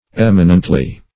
Eminently \Em"i*nent*ly\, adv.